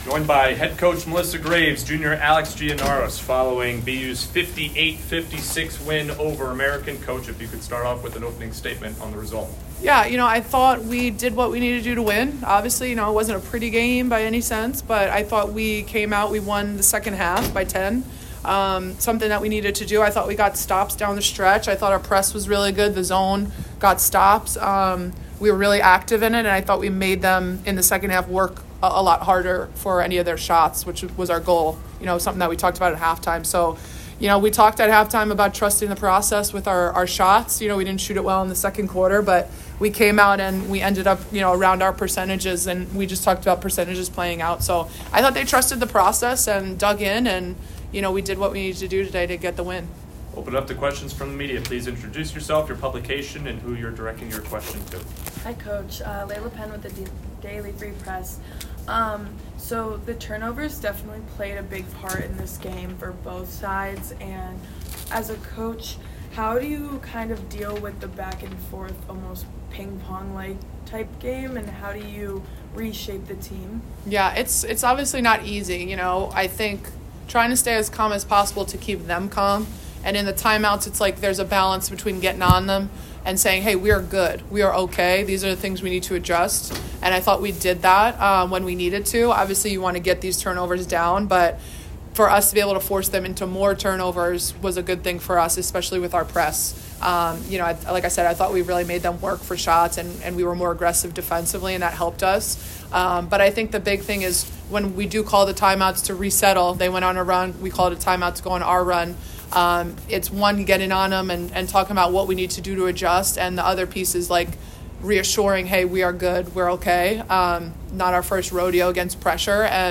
WBB_American_2_Postgame.mp3